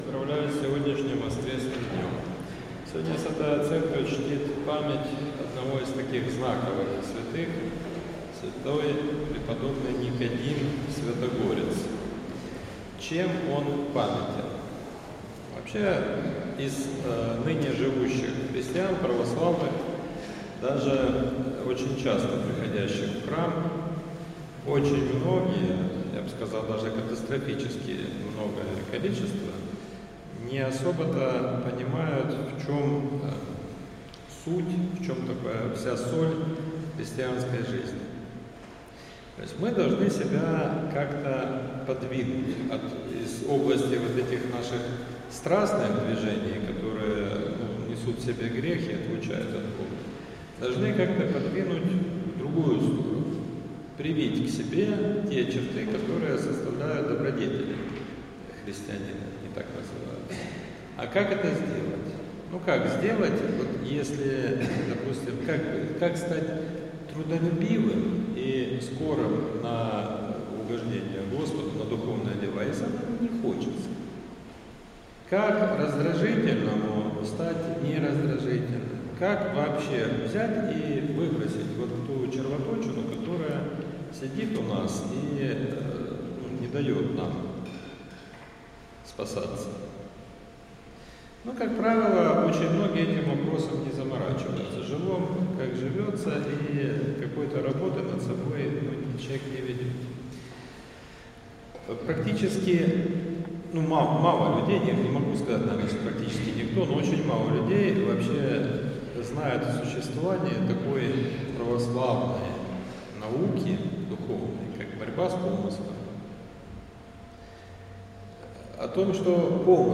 Проповедь в седьмую неделю по Пятидесятнице — Спасо-Преображенский мужской монастырь
В воскресение, 27 июля, в седьмую неделю по Пятидесятнице, на Божественной Литургии читался отрывок из Евангелия от Матфея (9:27-35).